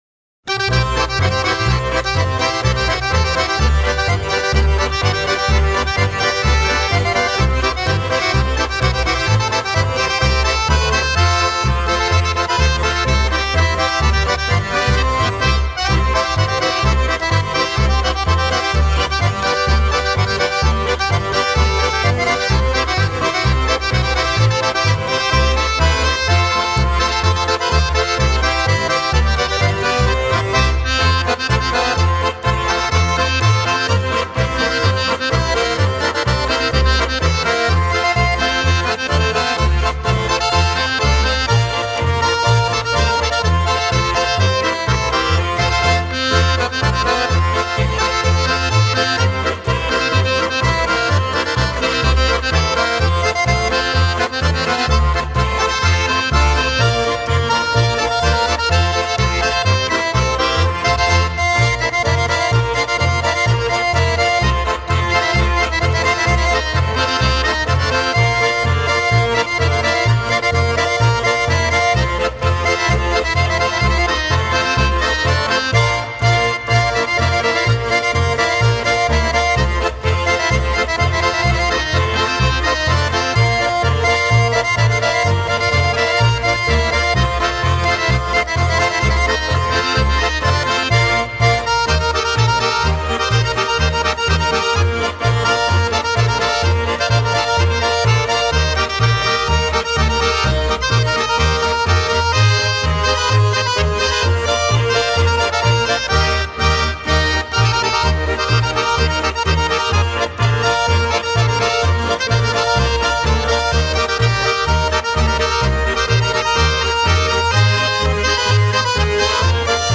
is a bloomy, full-flavoured programme